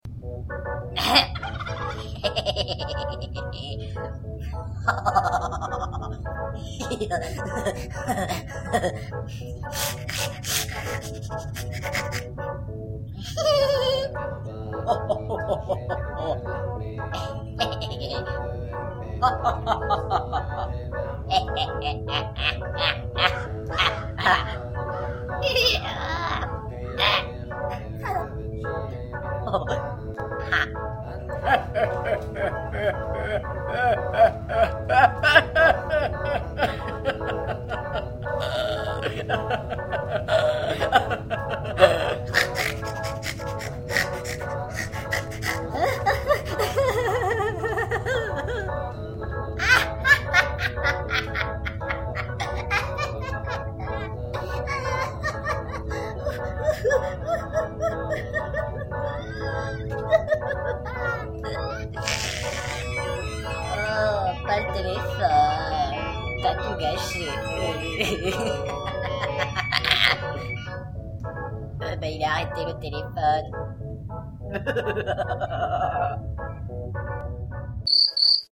3) Des rires à en mourir :
Des rires.mp3